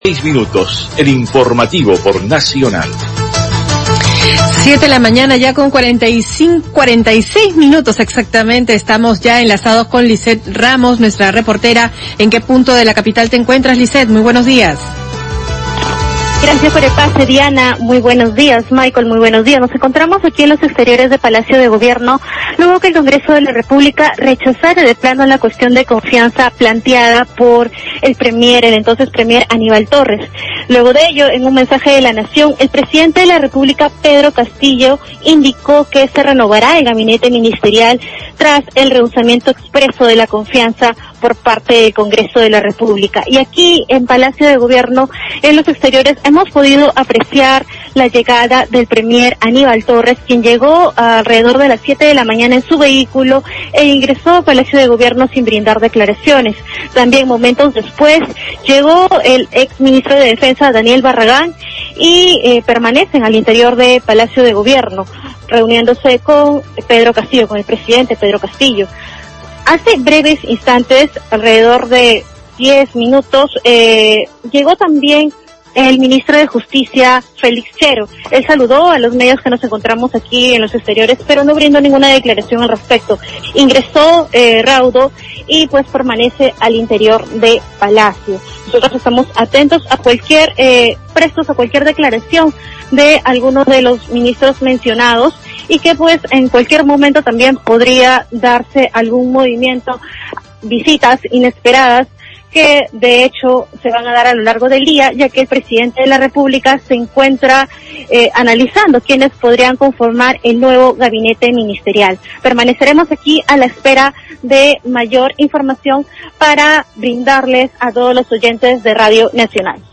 Enlace. Desde Palacio de Gobierno, se informa que el Congreso rechazó la cuestión de confianza planteada por el entonces premier Aníbal Torres.